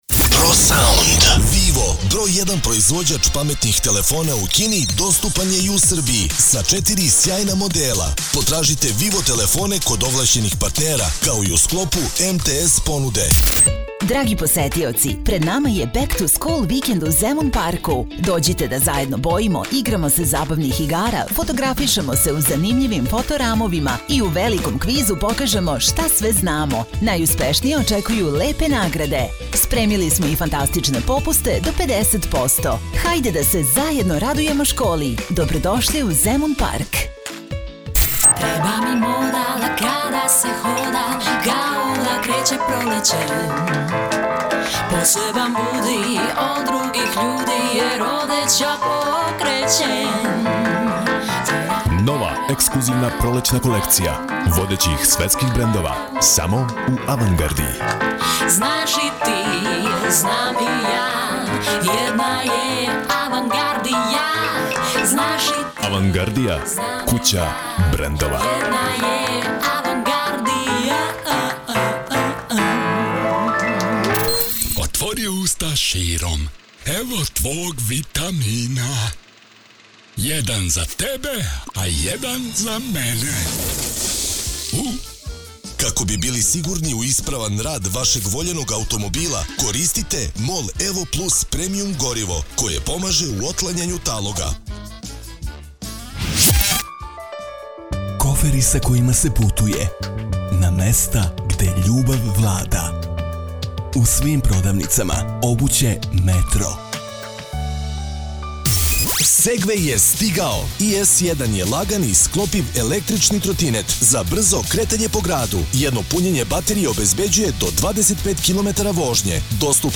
RADIO REKLAME